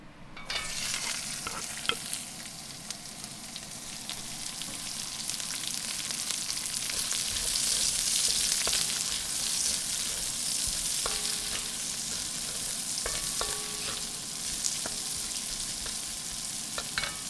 SFX炒菜07(Stir fry 07)音效下载